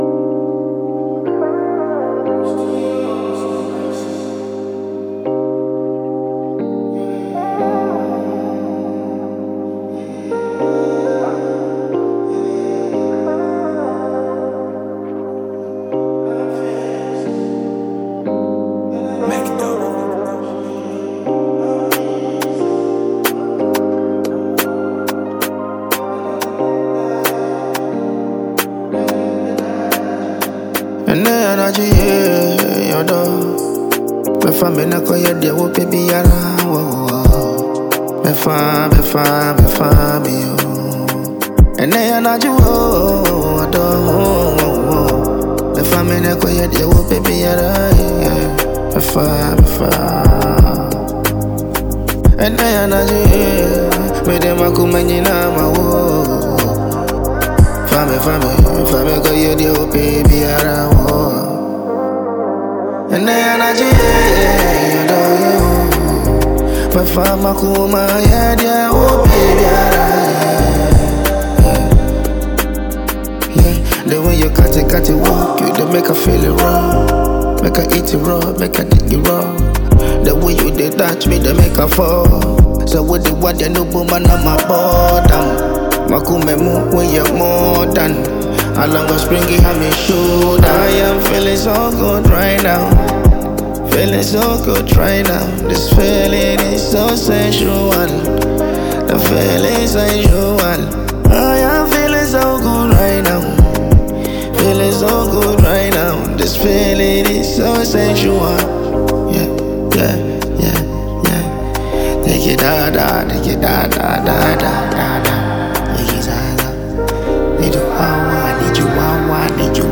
smooth and soulful Ghanaian song
Genre: Afrobeats / Soul